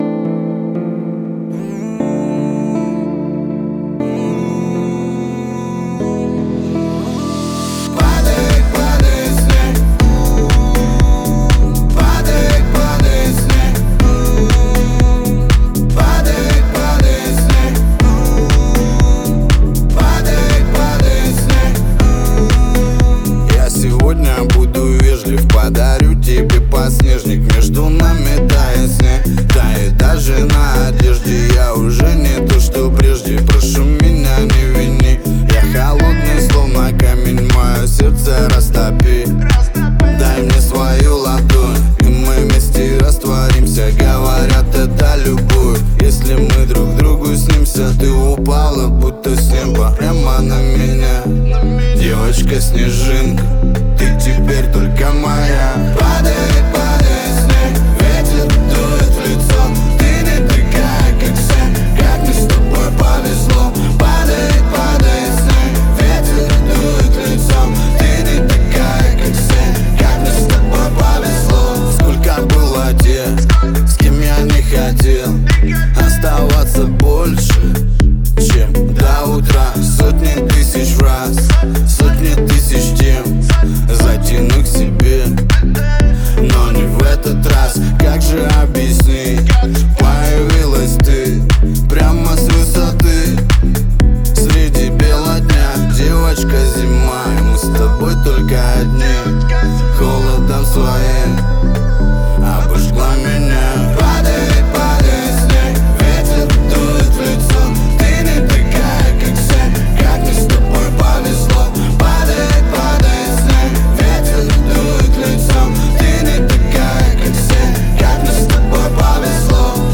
трогательная и мелодичная песня